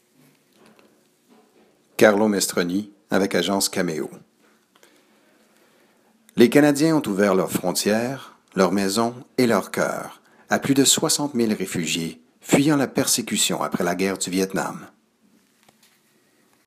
Narration - FR